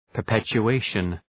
Προφορά
{pər,petʃu:’eıʃən}
perpetuation.mp3